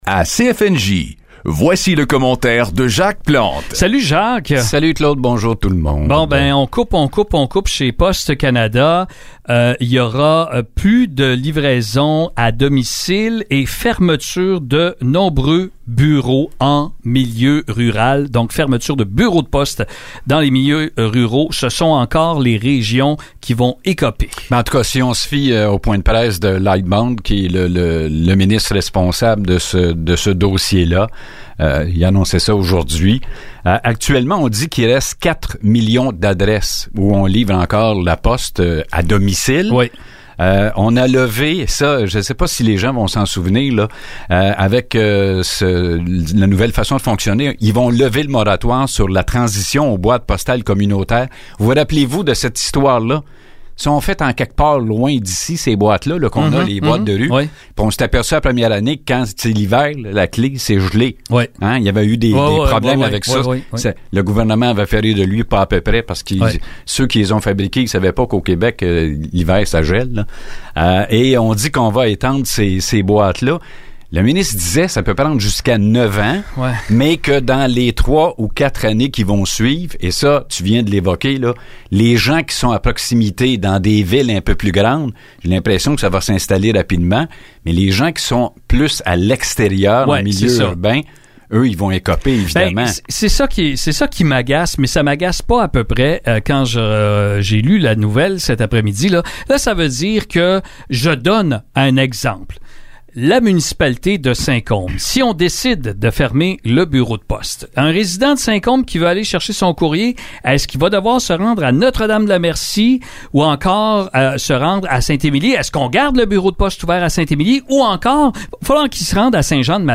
Les coupes à Postes Canada : Le commentaire